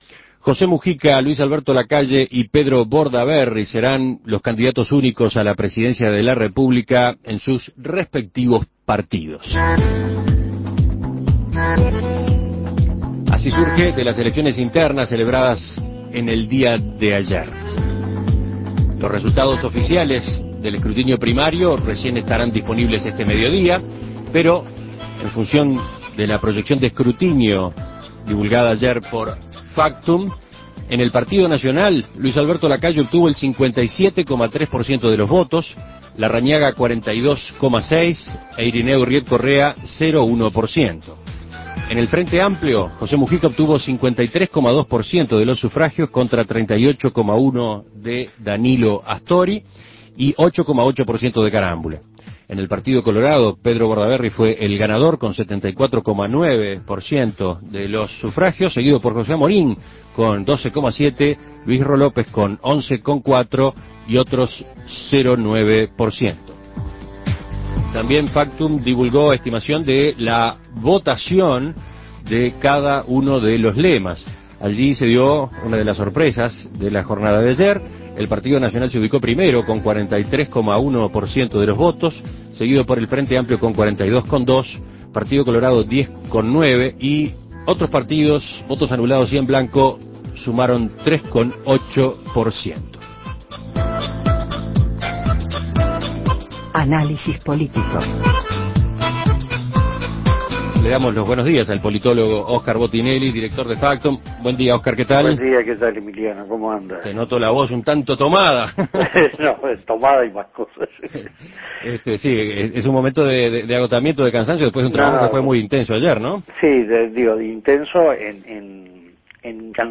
Análisis Político